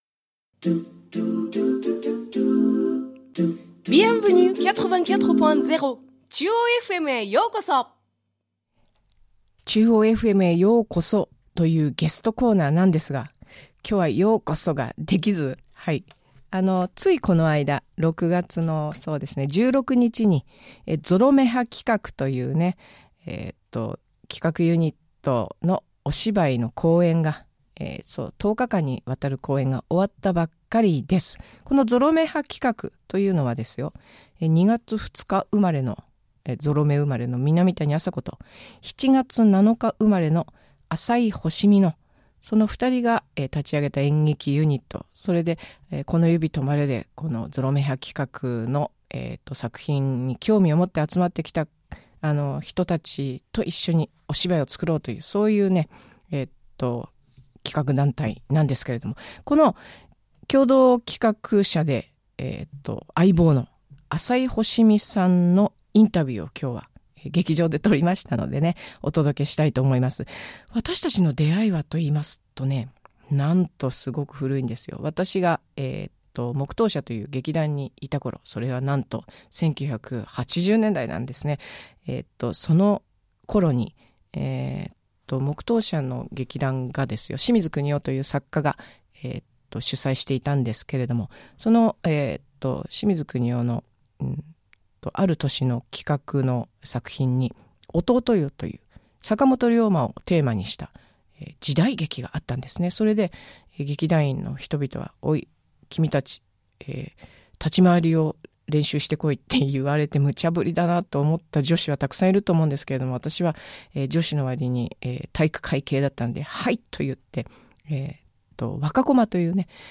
ゲストコーナーです 今日は１６日（日）に終了した ZOROMEHA企画「ぼくらは生れ変った木の葉のように２０１…